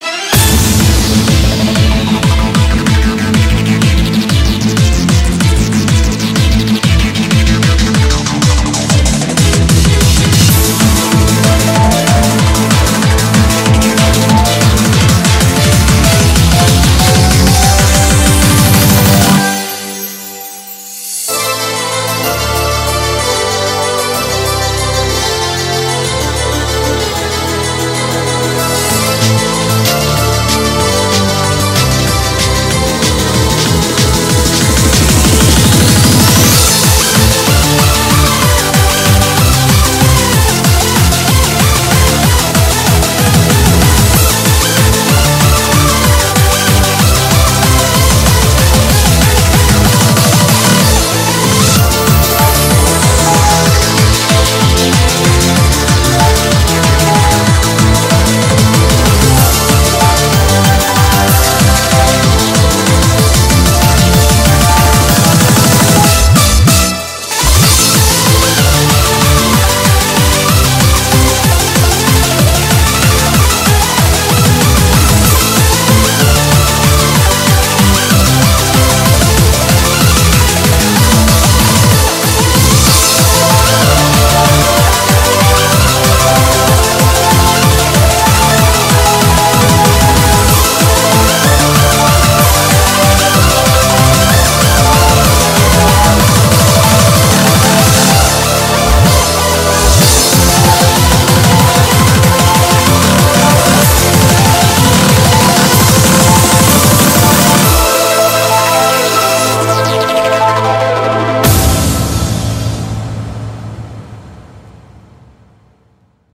BPM189
Audio QualityMusic Cut